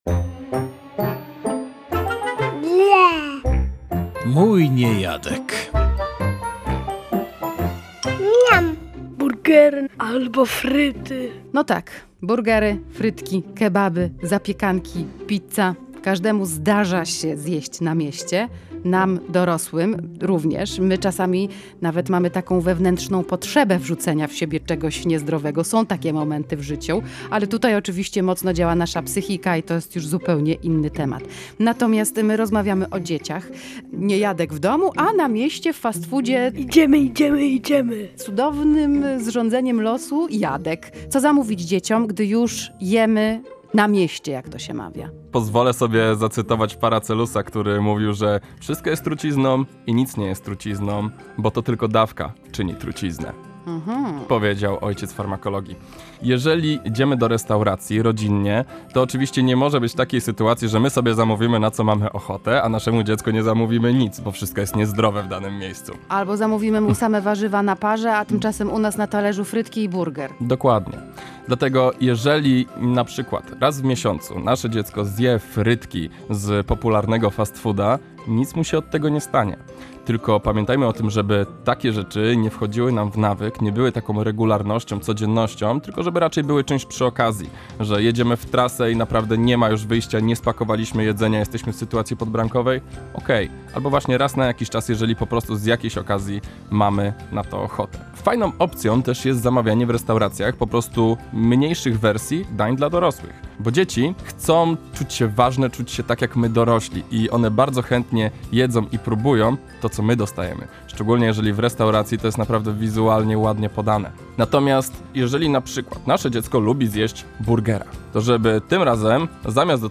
W audycji "Mój niejadek" rozmawialiśmy o jedzeniu "na mieście" oraz fast foodach.